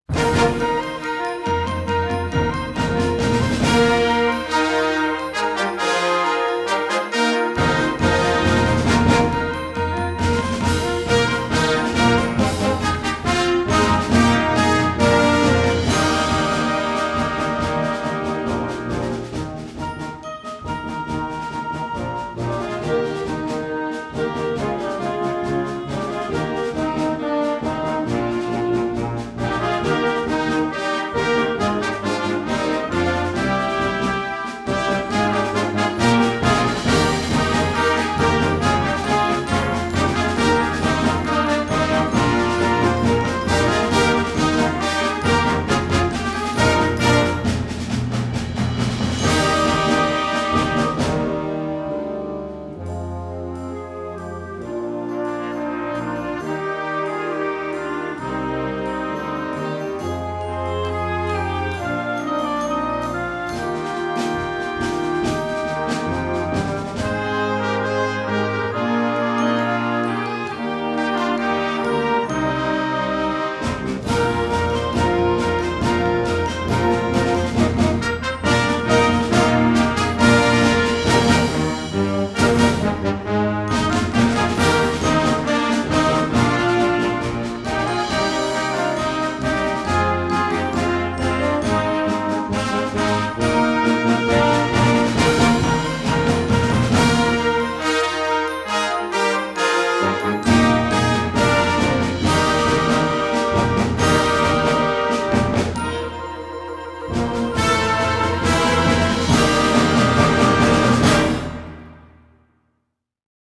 Flex Band